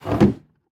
Minecraft Version Minecraft Version 1.21.5 Latest Release | Latest Snapshot 1.21.5 / assets / minecraft / sounds / block / barrel / close.ogg Compare With Compare With Latest Release | Latest Snapshot
close.ogg